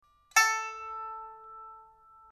pipa15.mp3